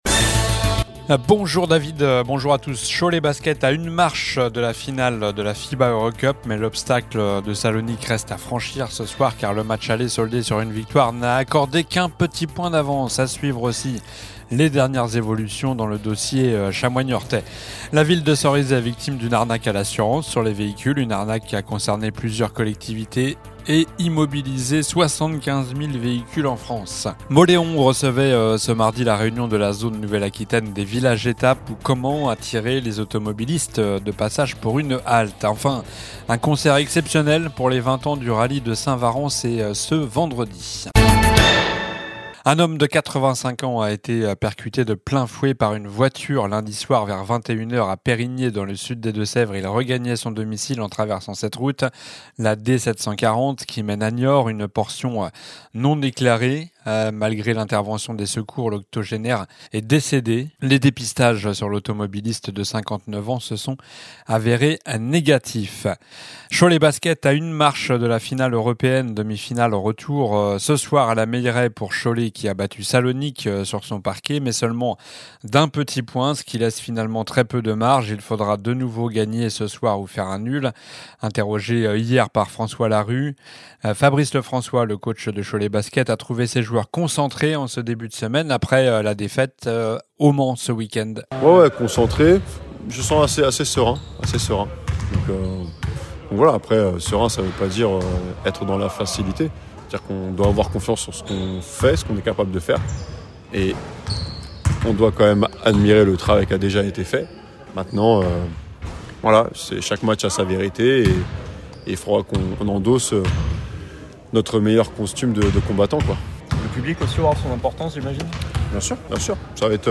Journal du mercredi 02 avril (midi)